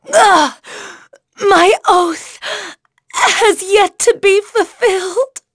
Aselica-Vox_Dead.wav